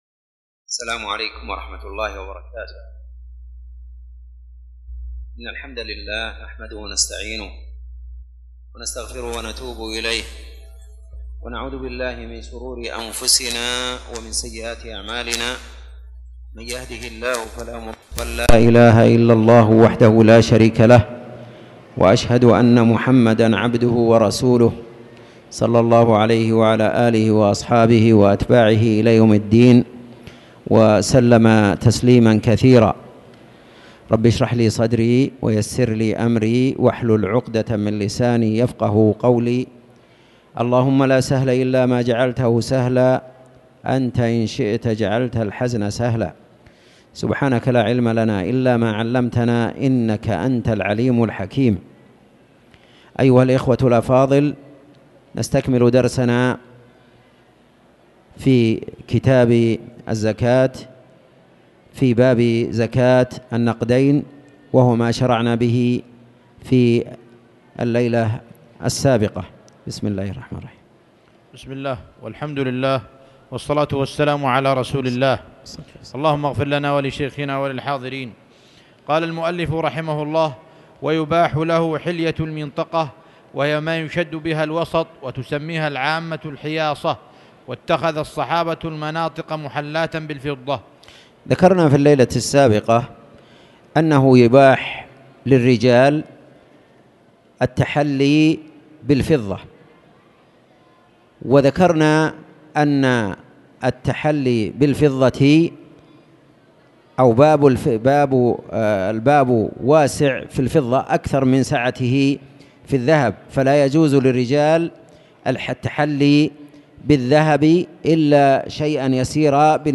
تاريخ النشر ٣ محرم ١٤٣٨ هـ المكان: المسجد الحرام الشيخ